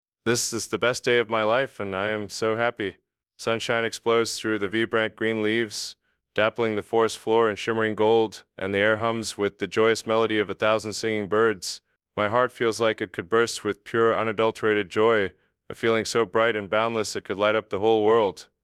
Rename [Elon Musk(Noise reduction)]This ......orld..mp3 to elon.mp3